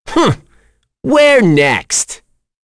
Mitra-Vox_Victory_b.wav